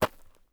Terrarum/assets/mods/basegame/audio/effects/steps/GRVL.3.wav at 8a1ff32fa7cf78fa41ef29e28a1e4e70a76294ea